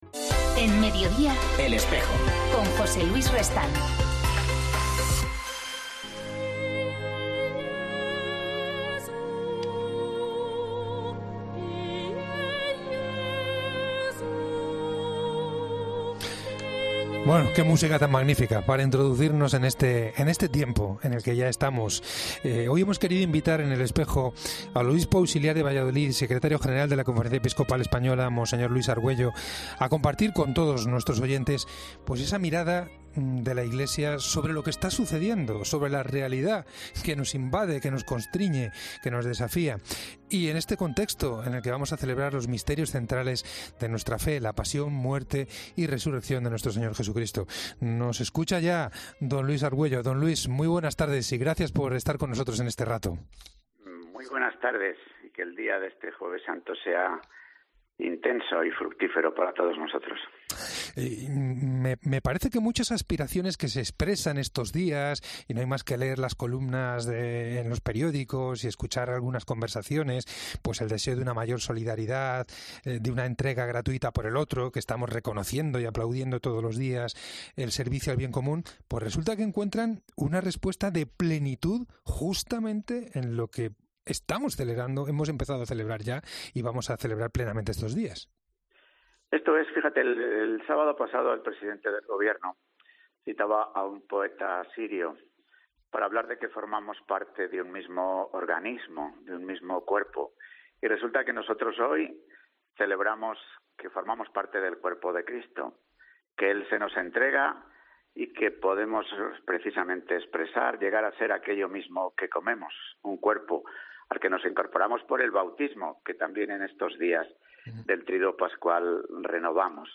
El secretario general de la CEE, mons. Luis Argüello habla en El Espejo sobre la Semana Santa especial que vivimos por el coronavirus